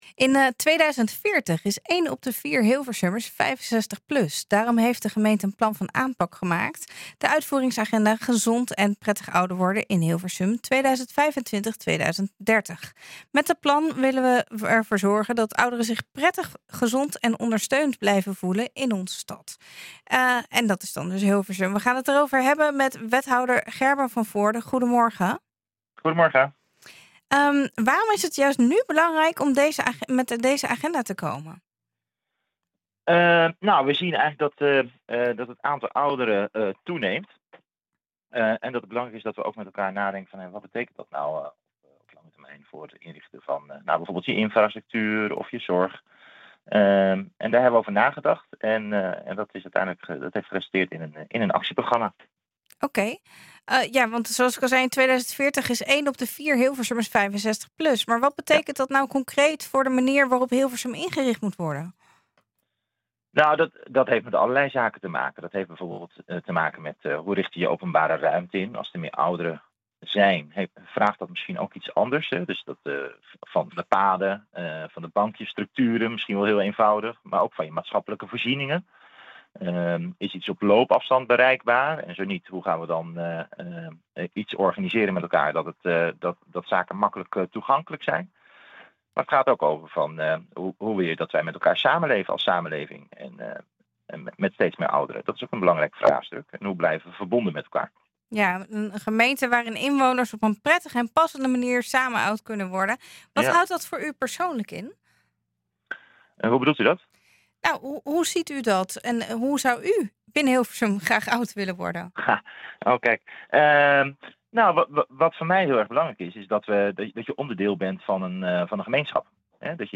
Met dat plan wil Hilversum ervoor zorgen dat ouderen zich prettig, gezond en ondersteund blijven voelen in de stad. We gaan het erover hebben met wethouder Gerben van Voorden.